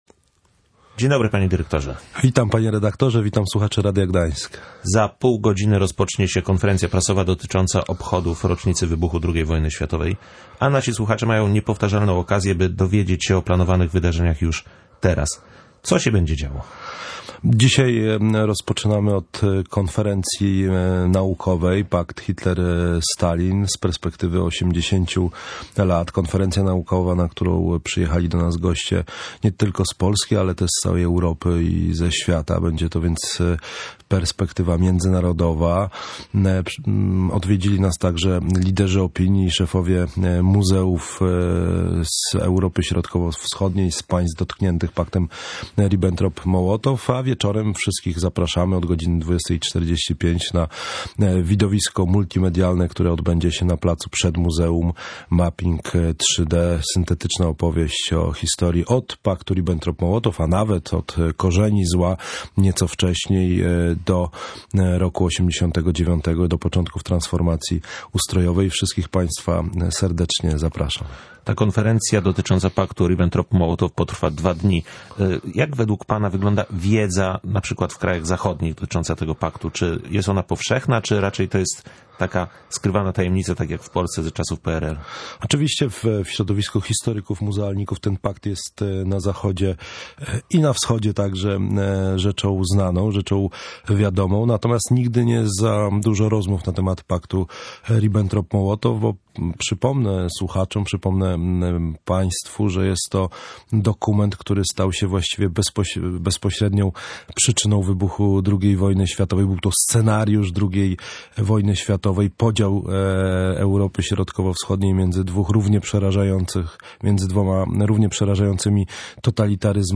Członkowie wyrazili pełne poparcie dla realizacji zadania – mówił w Radiu Gdańsk Karol Nawrocki, dyrektor Muzeum II Wojny Światowej. W czwartek Rada Muzeum II Wojny Światowej opiniowała koncepcję powstającego Muzeum Westerplatte i Wojny 1939.